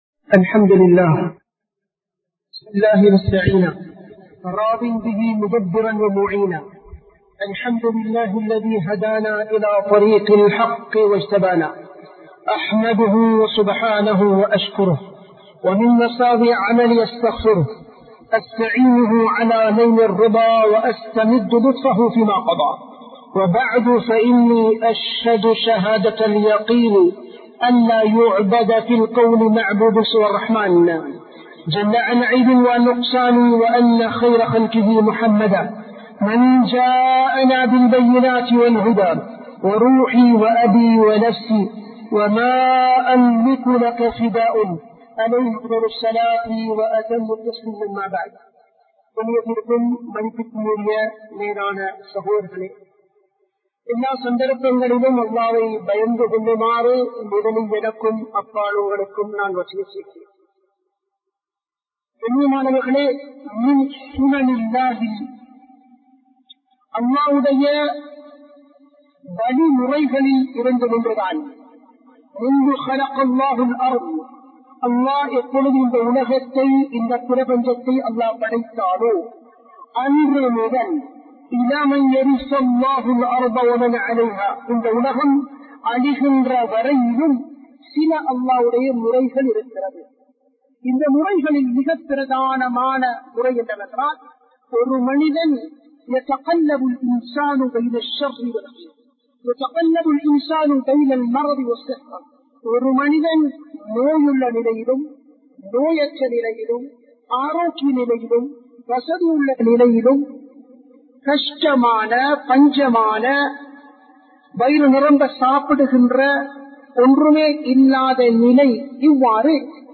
சோதனைகளை எவ்வாறு சமாளிப்பது? | Audio Bayans | All Ceylon Muslim Youth Community | Addalaichenai
Kollupitty Jumua Masjith